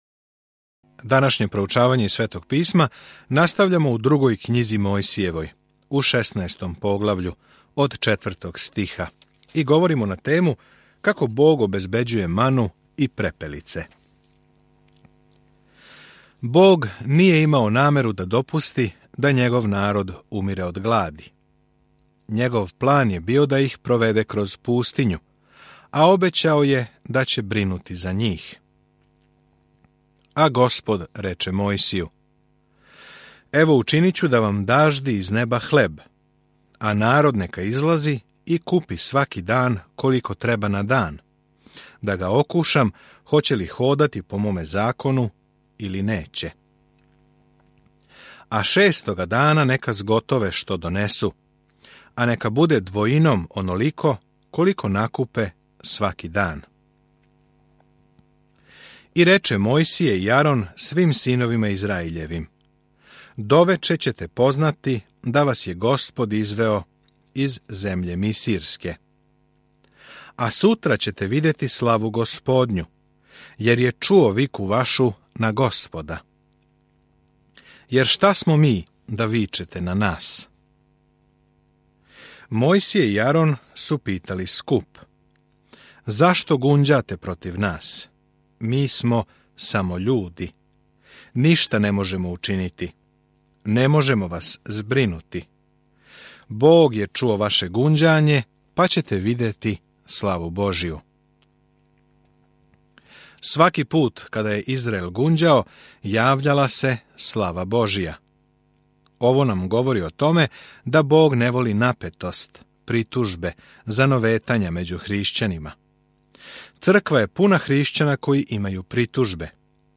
Свакодневно путујте кроз Излазак док слушате аудио студију и читате одабране стихове из Божје речи.